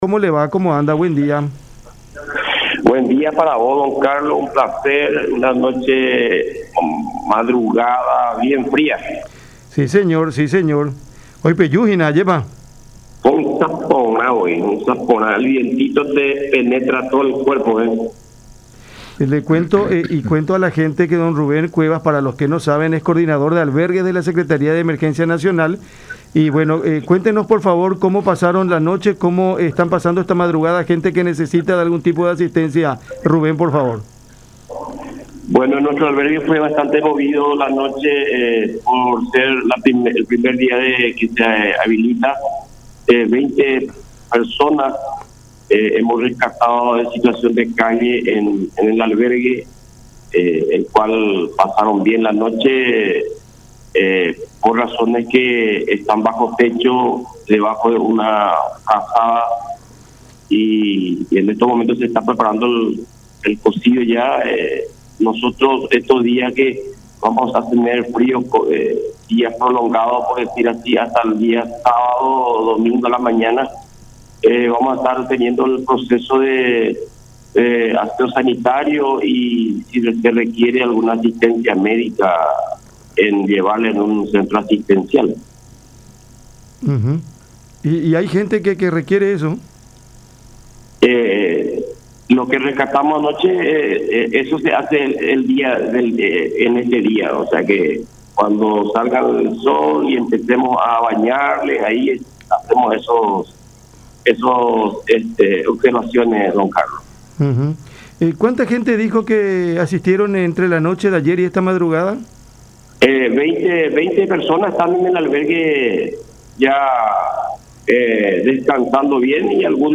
en charla con Cada Mañana a través de La Unión.